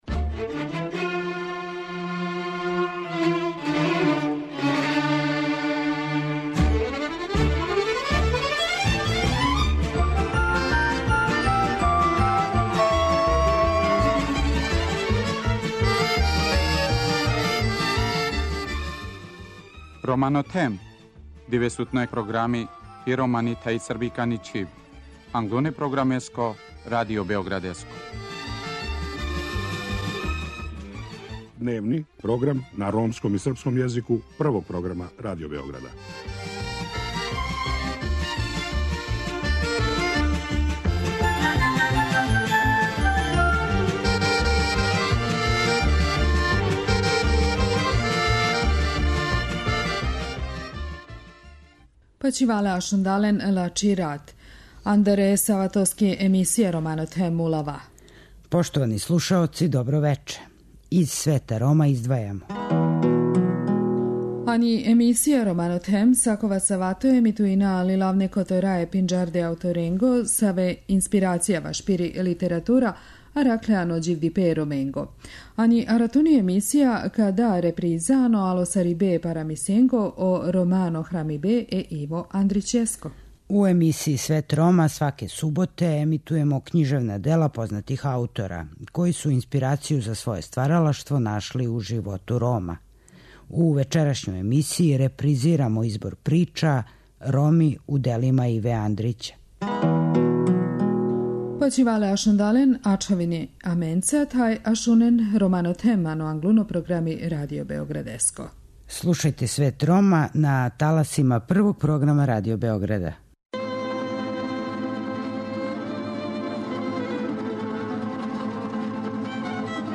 У емисији Свет Рома, сваке суботе емитујемо књижевна дела познатих аутора који су инспирацију за своје стваралаштво нашли у животу Рома. У вечерашњој емисији репризирамо избор прича 'Роми у делима Иве Андрића'.